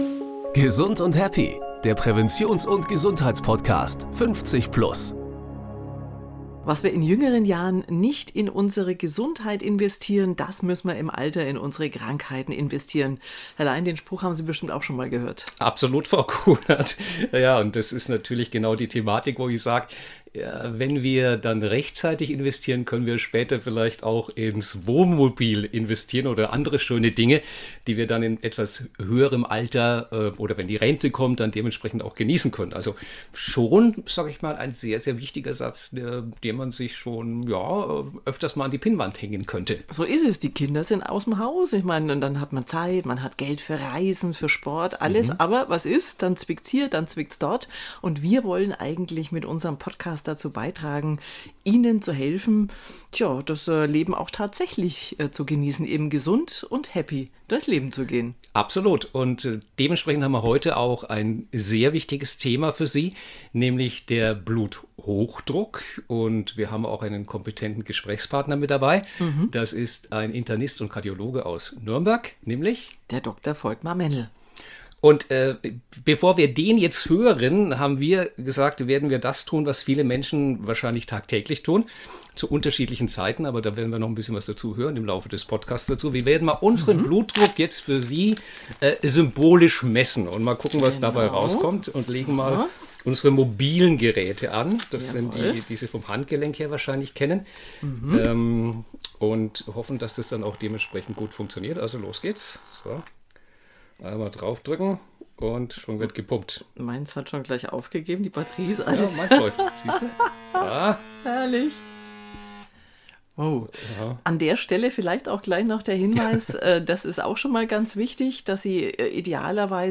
Wie also Bluthochdruck vorbeugen? Darüber plaudert das sympathische Moderatorenduo